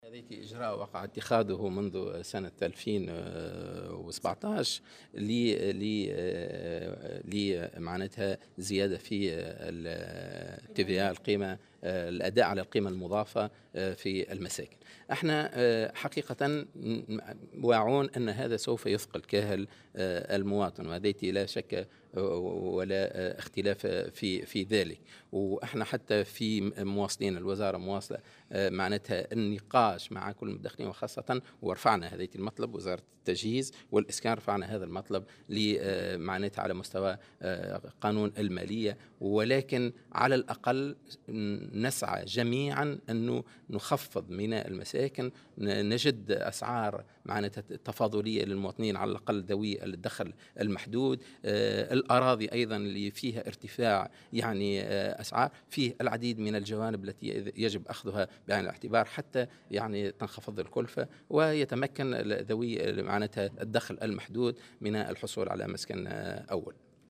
وأضاف في تصريح اليوم لمراسل "الجوهرة أف أم" على هامش ندوة حول آخر مستجدات قطاع السكن في تونس، أن الوزارة ستواصل النقاش مع كافة المتدخلين من أجل أن تكون القيمة تفاضلية في ثمن الأراضي والمساكن بالنسبة لذوي الدخل المحدود.